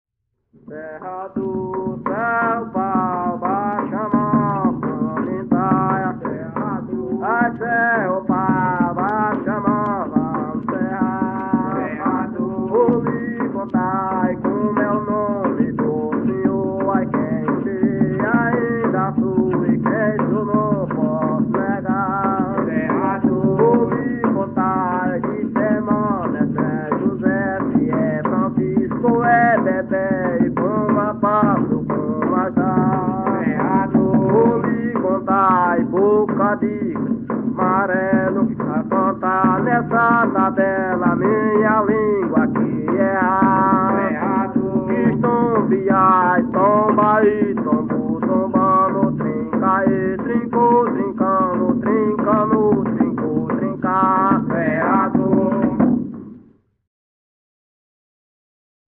Coco embolada – “”Serrador”” - Acervos - Centro Cultural São Paulo